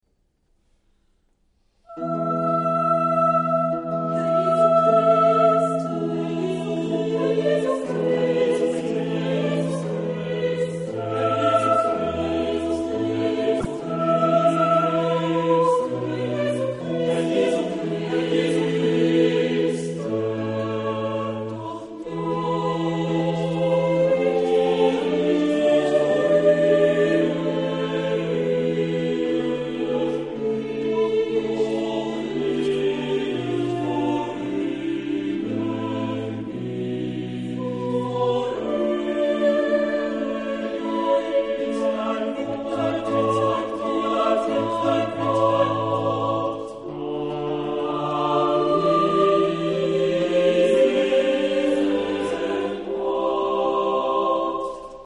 Genre-Style-Forme : Sacré ; Baroque ; Motet
Type de choeur : SSATB  (5 voix mixtes )
Instruments : Orgue (1) ; Clavecin (1)
Tonalité : la majeur